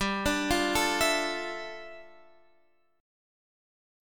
G513 chord {3 5 3 5 5 3} chord